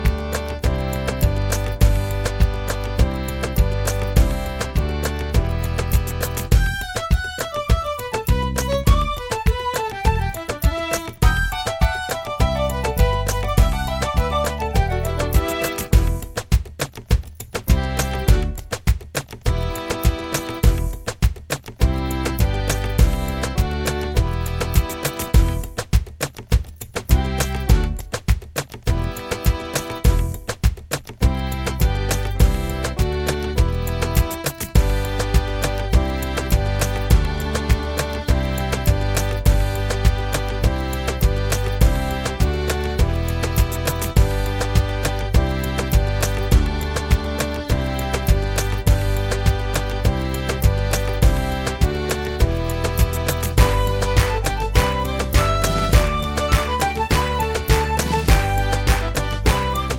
Minus Main Guitar For Guitarists 2:59 Buy £1.50